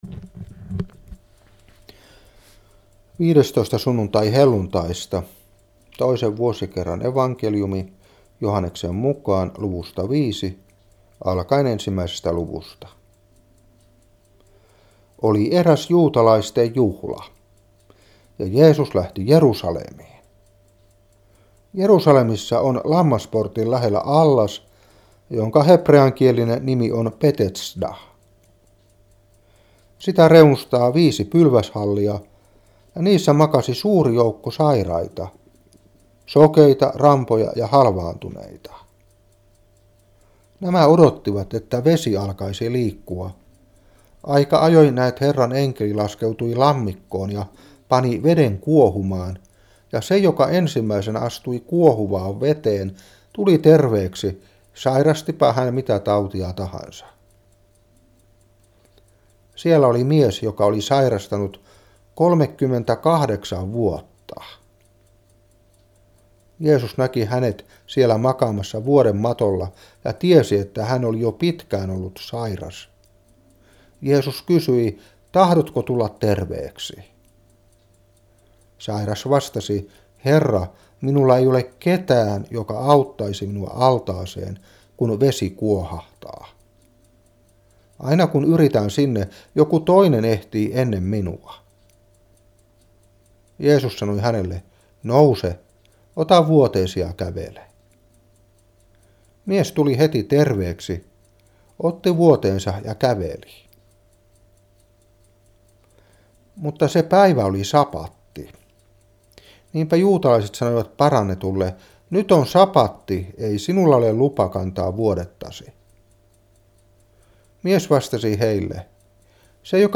Saarna 1997-8. Joh.5:1-15.